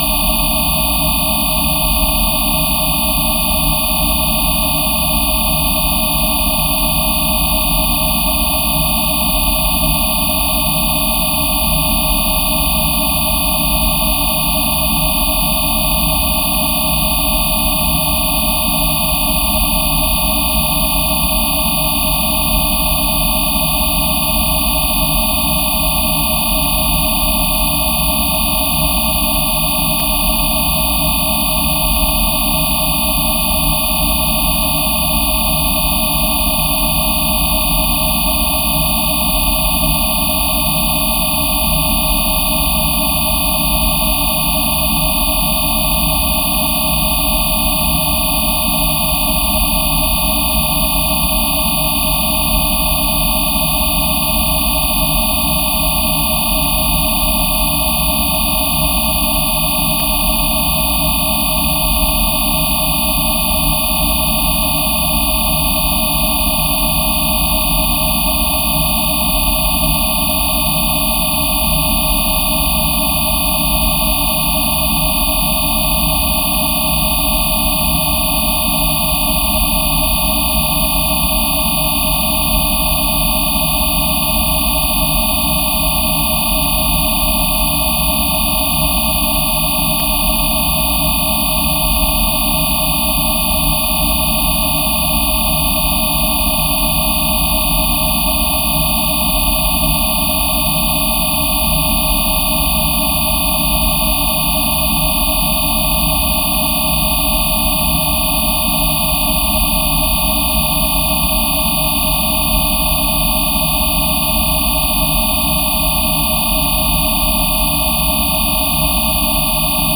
Горе је биорезонанти звучни документ витамина Д3.